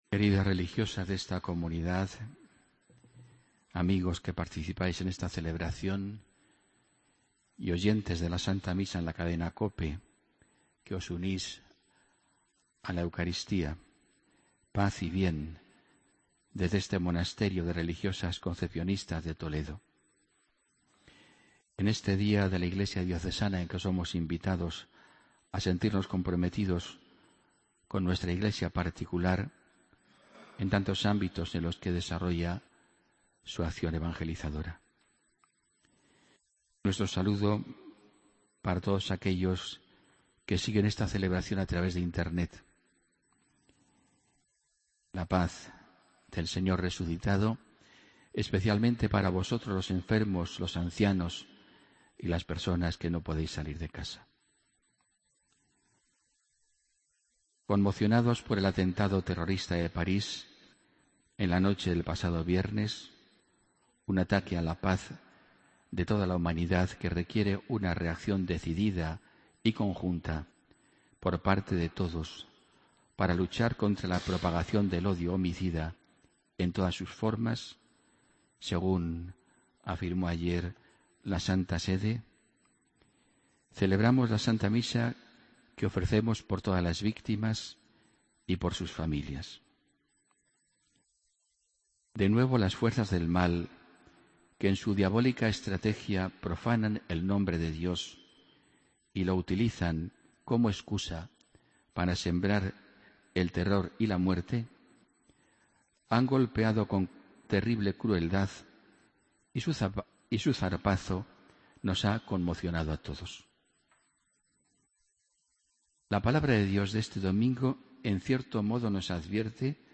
Homilía del domingo 15 de noviembre de 2015